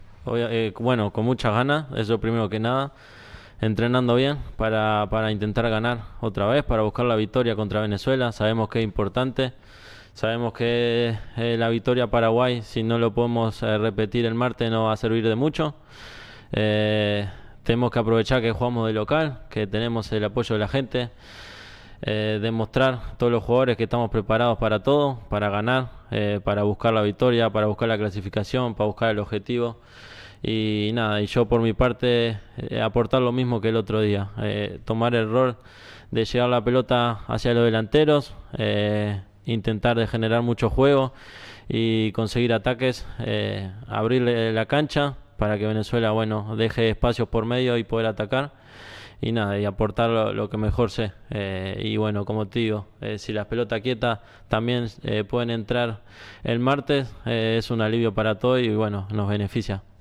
El jugador del Real Madrid Federico Valverde dijo en conferencia de prensa este domingo que «la victoria de Paraguay si no la podemos repetir el martes», contra Venezuela en el Estadio Centenario, «no va a servir de mucho».